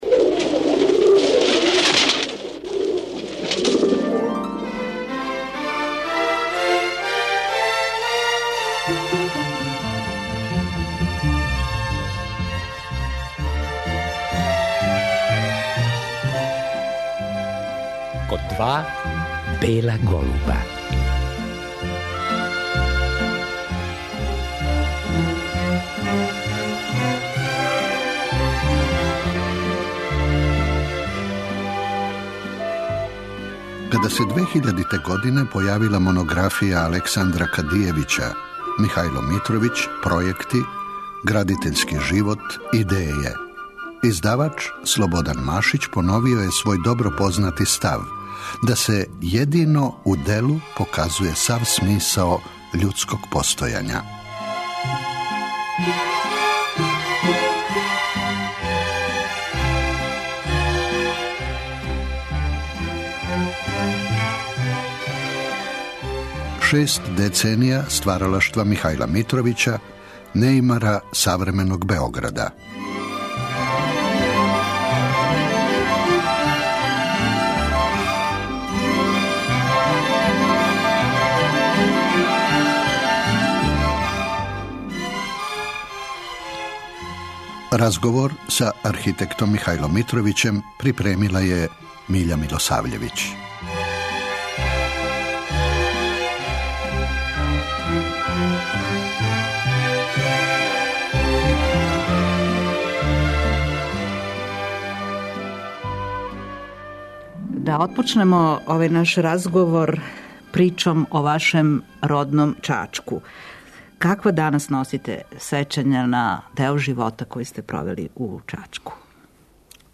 Тим поводом снимили смо овај разговор који вечерас поново емитује.